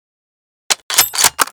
bolt.ogg